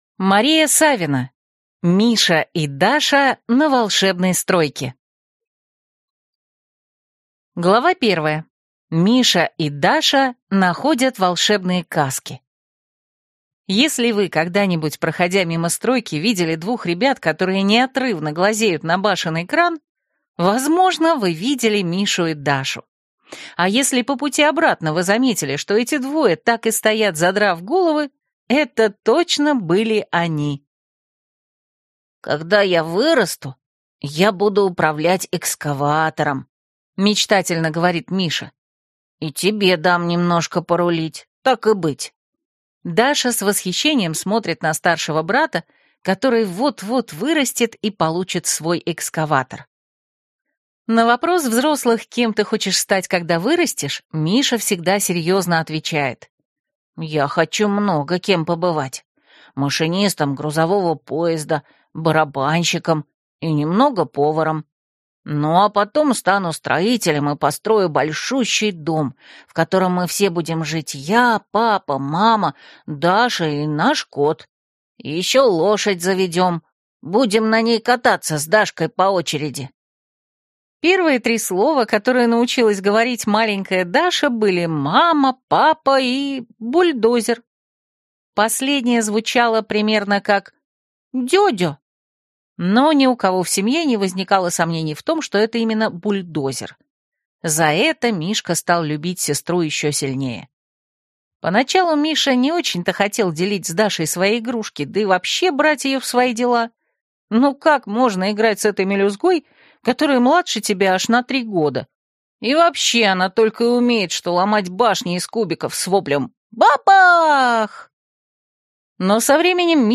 Аудиокнига Миша и Даша на Волшебной Стройке | Библиотека аудиокниг